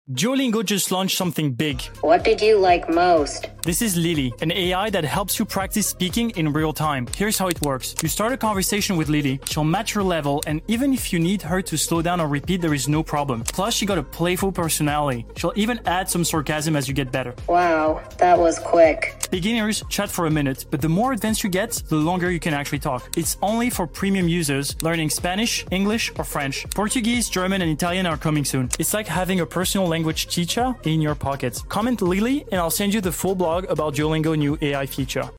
Here’s the scoop: 💬 With Duolingo’s “Video Call” feature, you can now chat with Lily, a playful AI character that adapts to your language level. • Lily adjusts to your pace, slowing down or repeating when necessary.